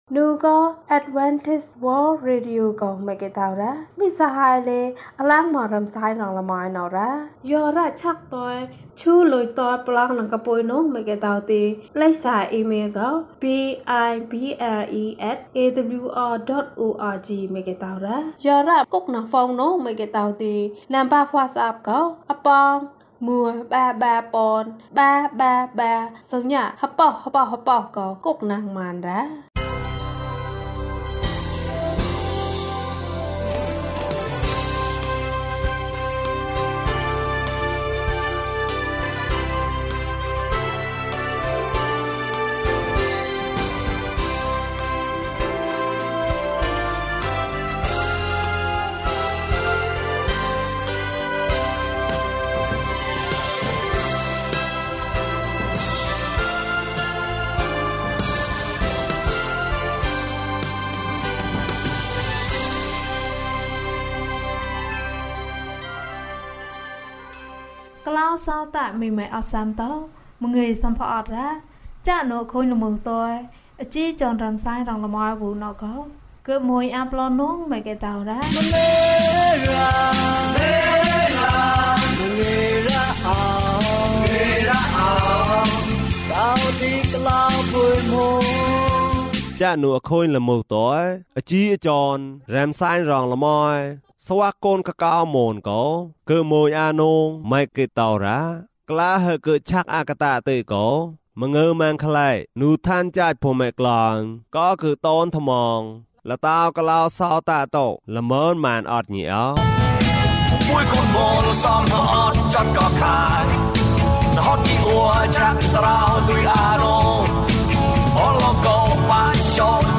ဘုရားသခင်နှင့်အတူ ကြီးပွားပါ။၀၂ ကျန်းမာခြင်းအကြောင်းအရာ။ ဓမ္မသီချင်း။ တရားဒေသနာ။